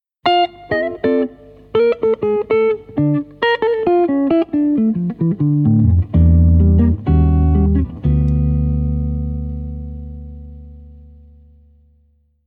Ibanez Archtop 7 cordes Brown Burst
Une guitare Archtop 7 cordes qu' Ibanez a produit en petite quantité.
micro grave